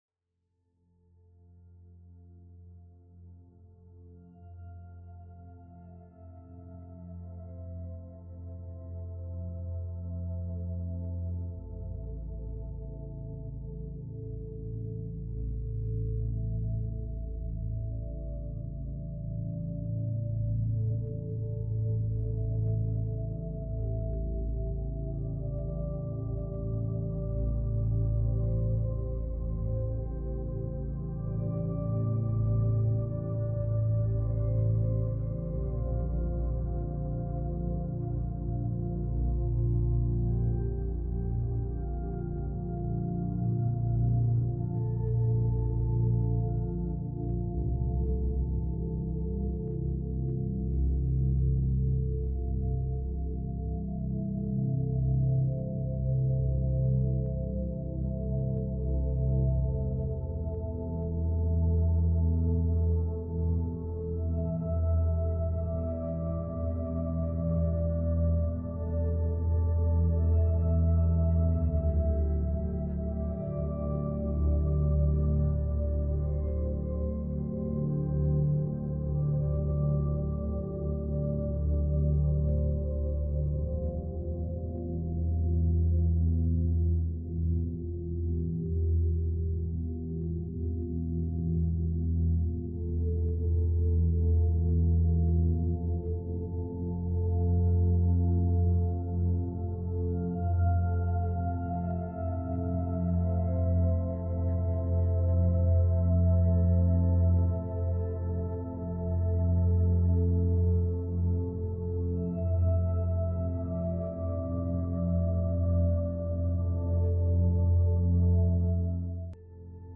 Calm Chic sound rituals - a softer way to focus.
Binaural Beats Meditation for Sleep 7.83 Hz - New Moon Reset for the Fire Horse Year Play episode February 16 59 mins Bookmarks Episode Description Calm Chic sound rituals - a softer way to focus.